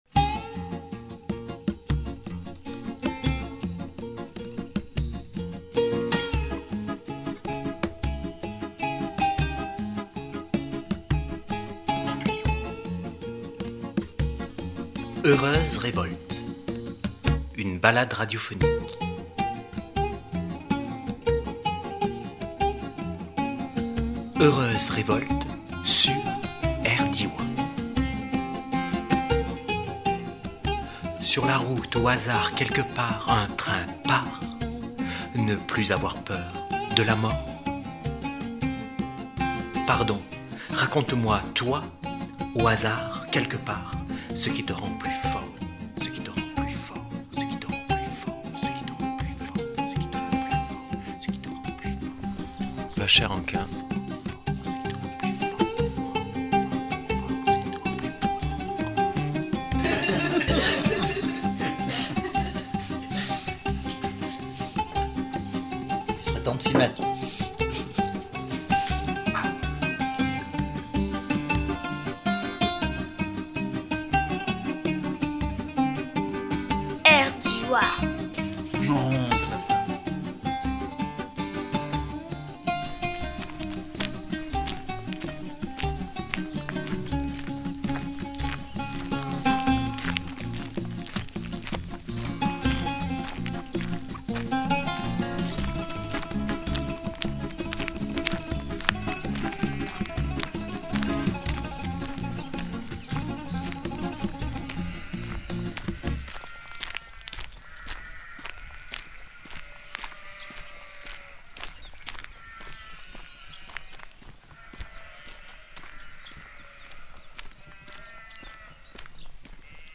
Autour d’un micro de radio les uns et les autres se parlent et se répondent sous un soleil d’été du mois de février, en regardant pointer ce que Pablo Servigne appelle “l’effondrement”. De Vachères en Quint jusqu’en Provence, écoutez les pas d’un reporter manchot.